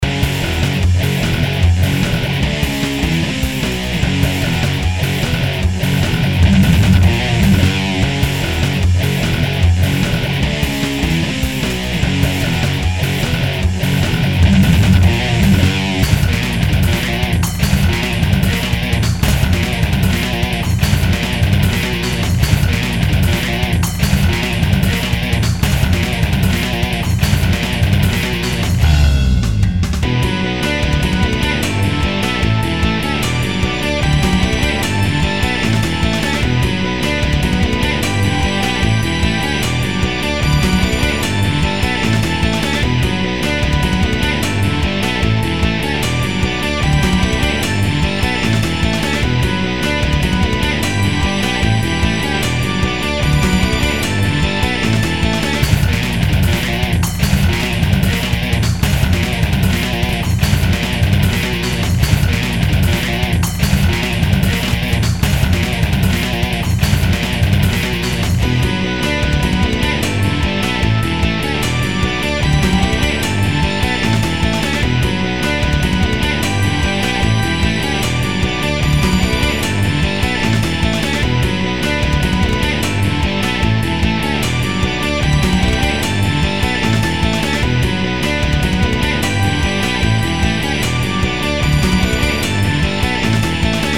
:: MUSIQUE INSTRUMENTALE ::